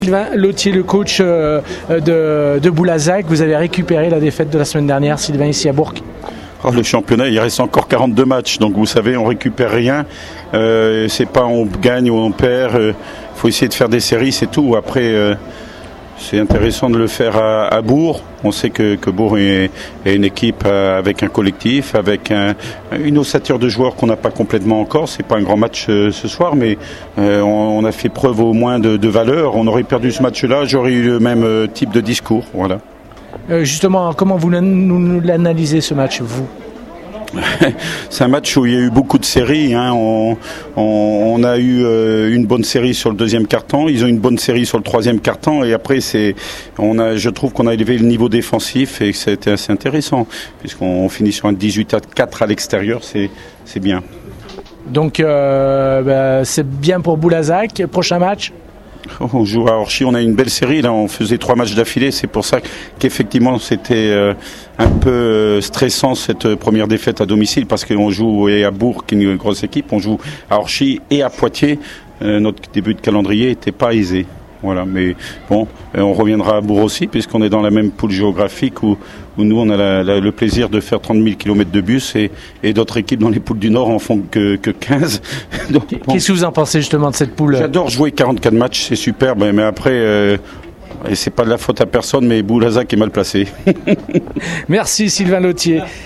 On écoute les réactions au micro